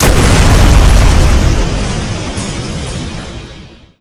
exploder_big_explosion.wav